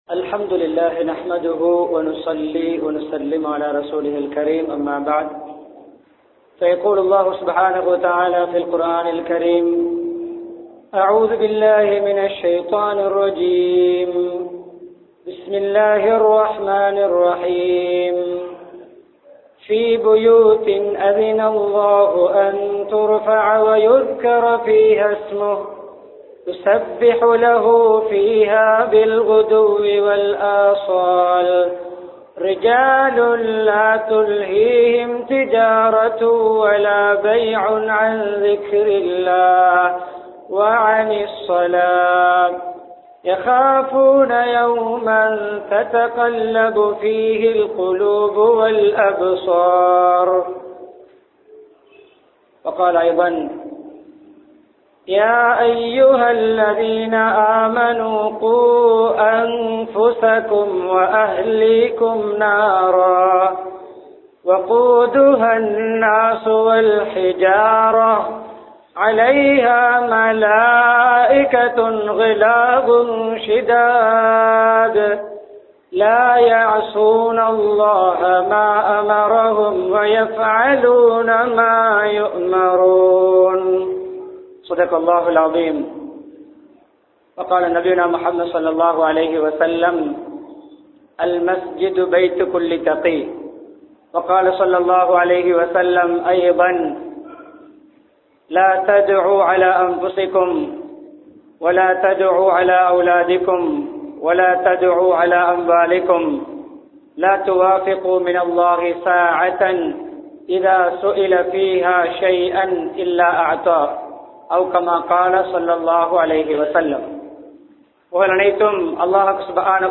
மஸ்ஜிதும் குழந்தை வளர்ப்பும் | Audio Bayans | All Ceylon Muslim Youth Community | Addalaichenai
Rathnapura, Azeeziya Jumua Masjidh